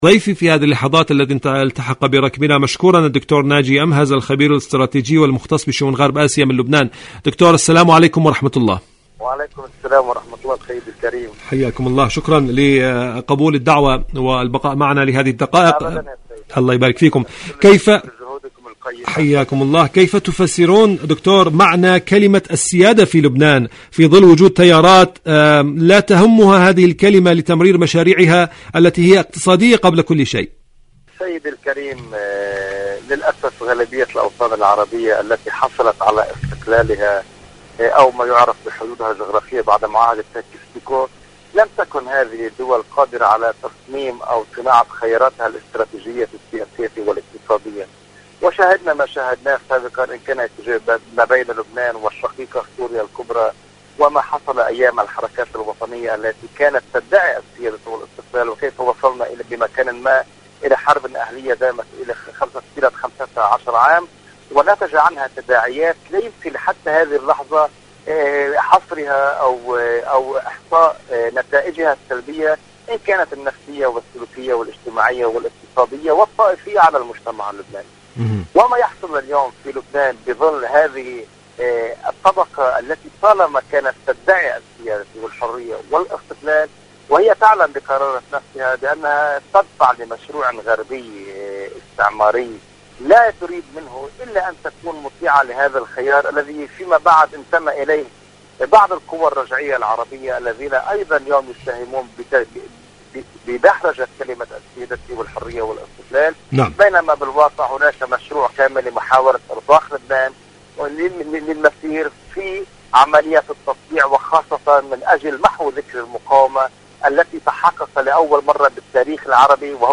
إذاعة طهران-أرض المقاومة: مقابلة إذاعية